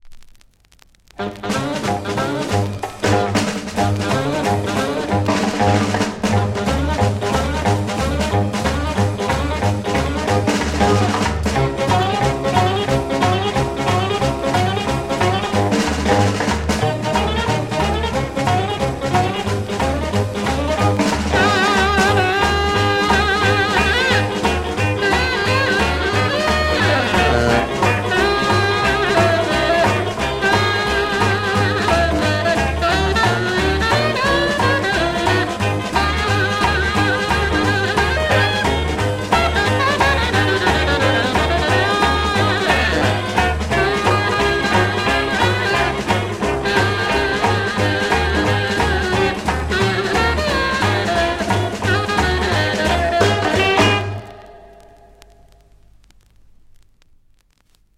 French Rock'n'roll Jazz
4 instrumental rock'ab